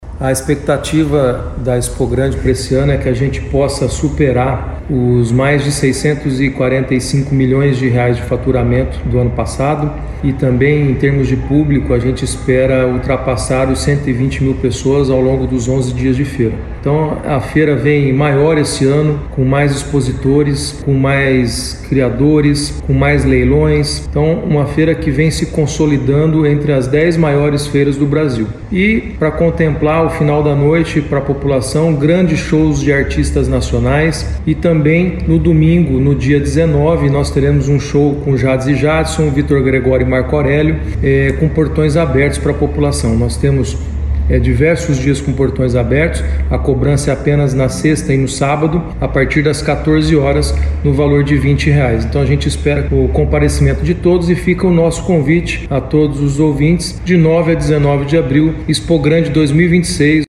conversou com a equipe do programa Agora 104 da FM Educativa MS 104.7 sobre as expectativas para o primeiro dia do evento.